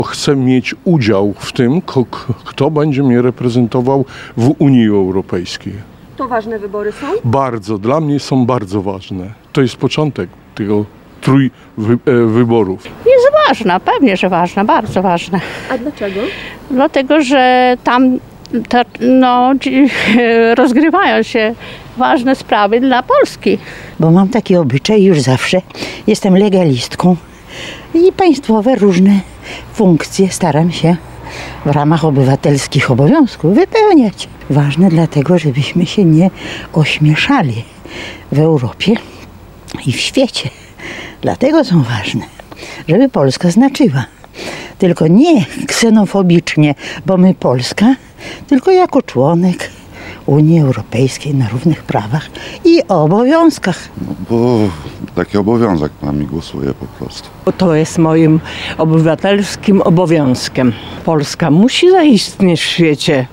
Zapytaliśmy mieszkańców Suwałk, dlaczego zdecydowali się iść do urny.